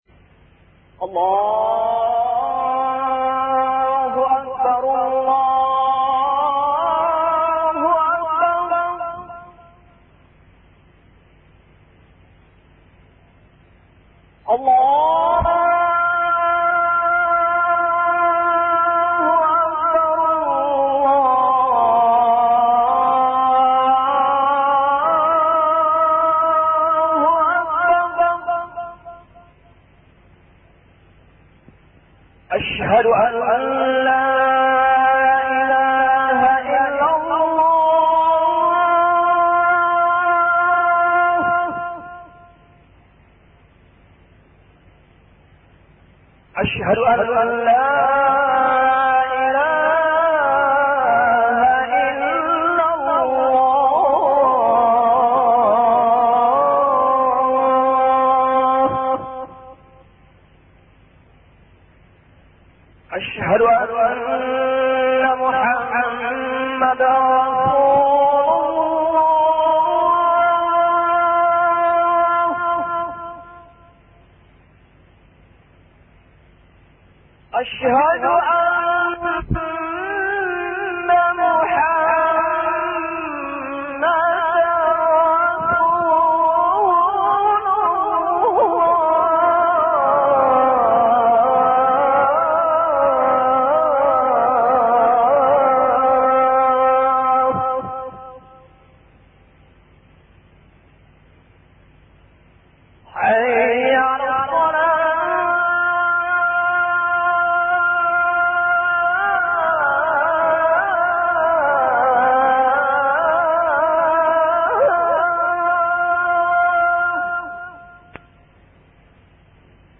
أناشيد ونغمات
عنوان المادة أذان-1